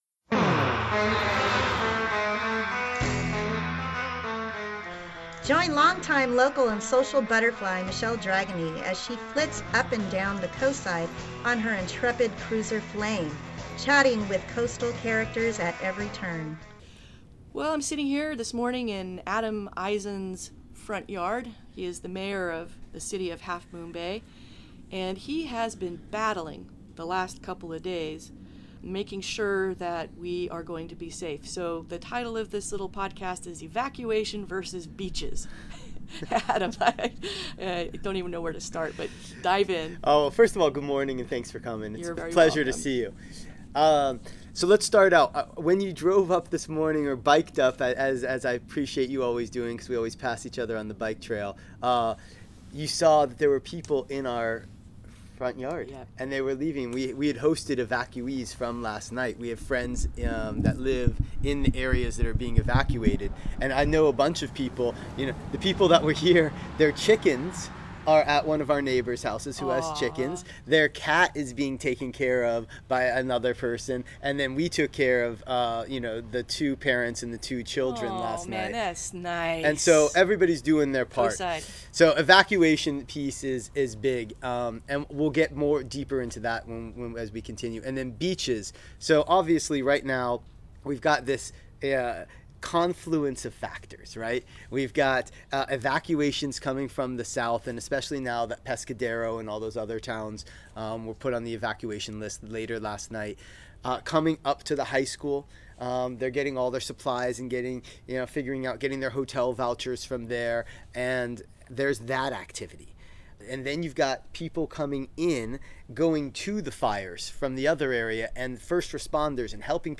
PODCAST. A Coastal Butterfly interview with City of Half Moon Bay’s Mayor, Adam Eisen, in his front yard, socially distanced, on August 21st, 2020 at 9:00am.